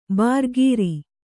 ♪ bārgīri